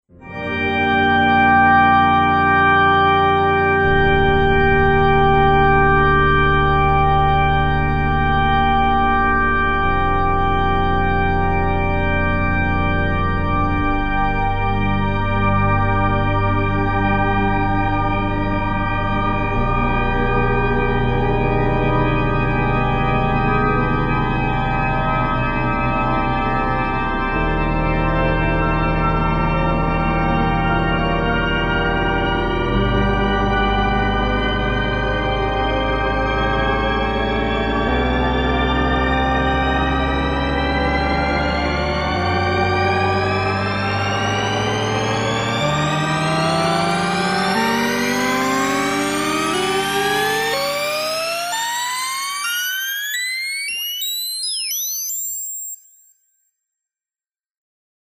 Organ Phase
Experiment with pitch shift